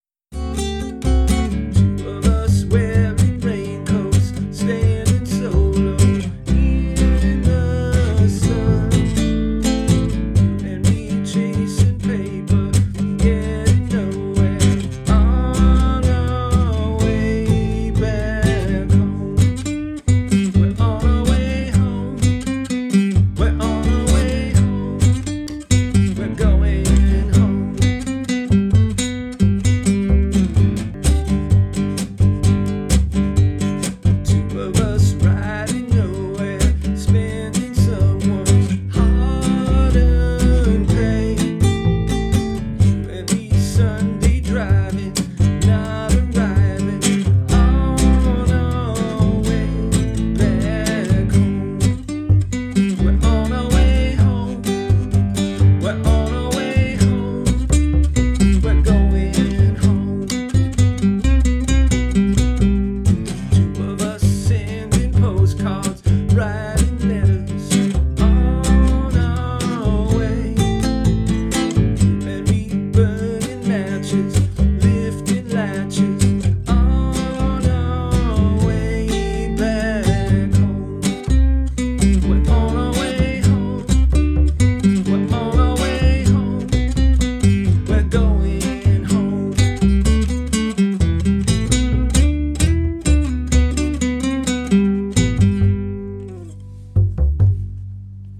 Genre: Pop rock + folk rock.